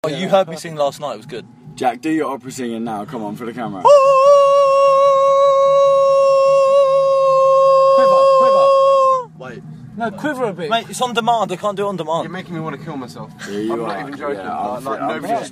opera singing